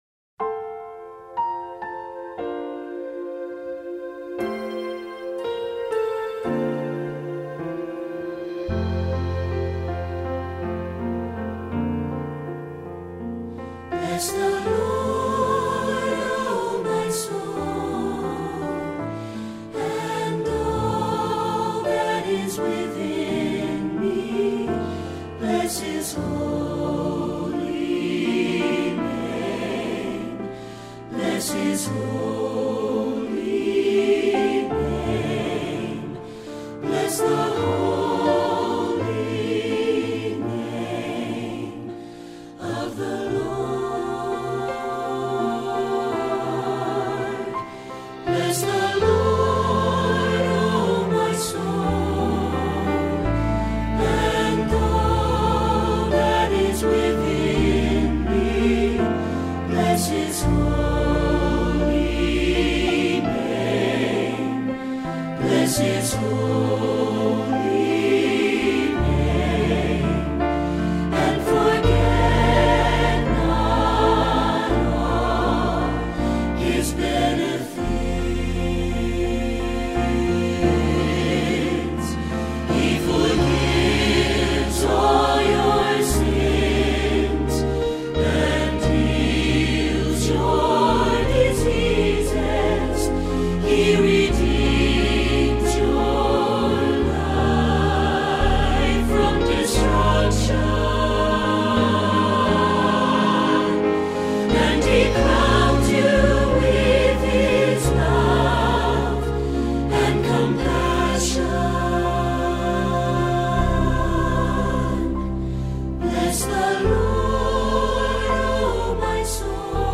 2026 Choral Selections